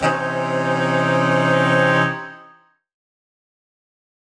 UC_HornSwellAlt_Cmajminb6.wav